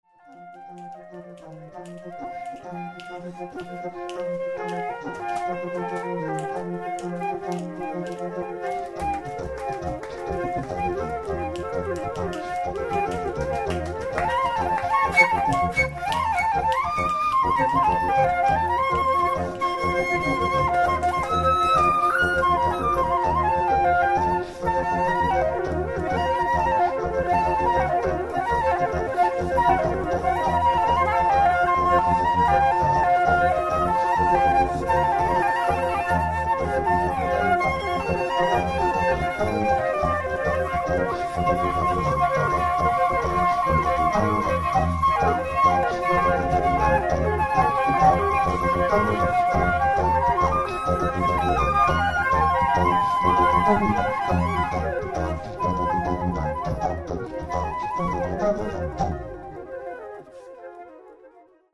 flute
live eletronics
E' un progetto di stampo minimalista